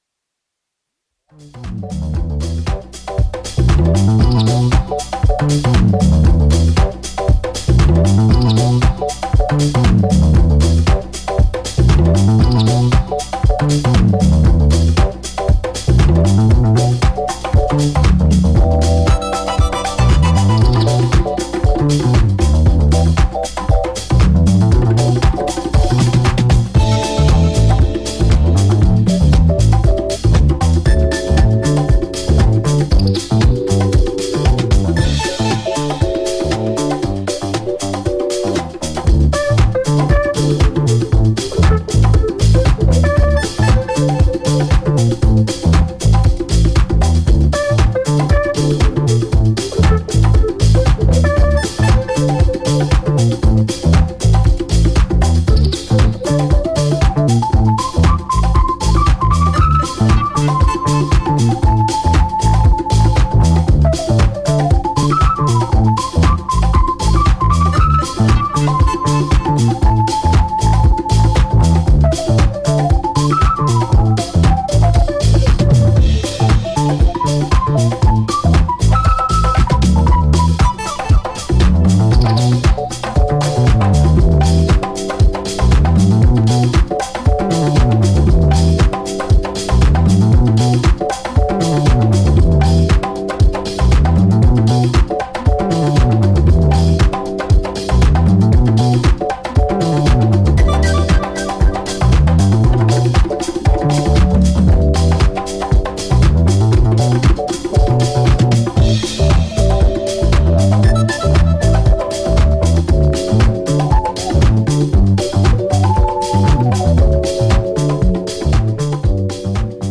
ジャンル(スタイル) NU DISCO / HOUSE / RE-EDIT